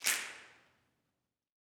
SNAPS 23.wav